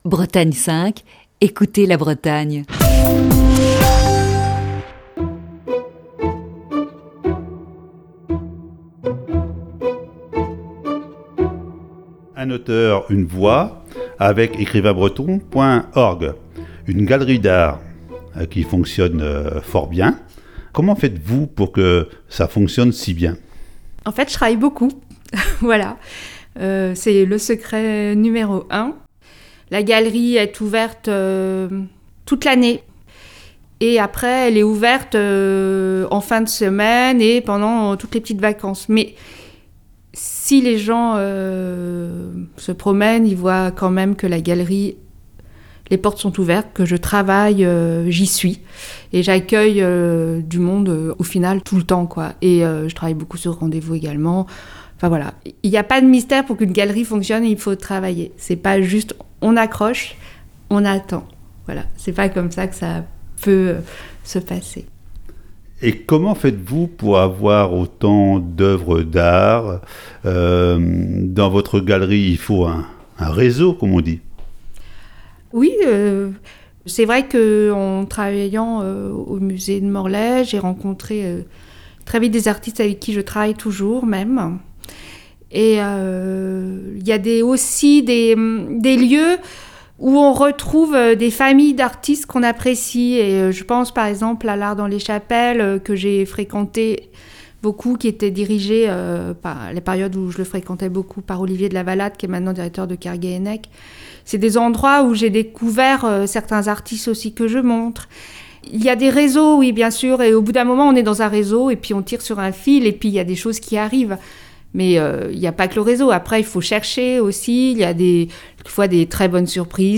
Voici ce jeudi la quatrième partie de cette série d'entretiens.